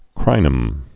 (krīnəm)